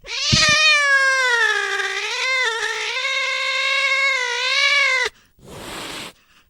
black_cat.ogg